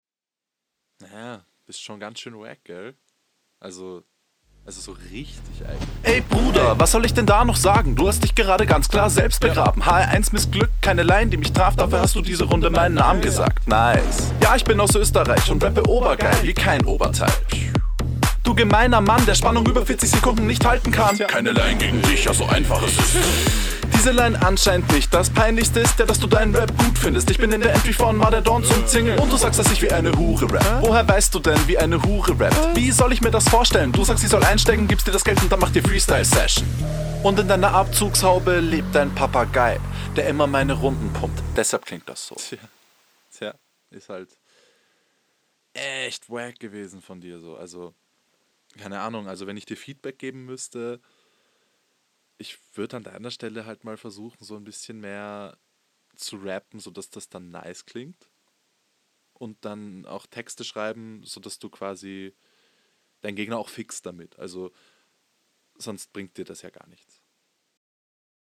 Was soll man hier großartig sagen, flowlich alles stabil.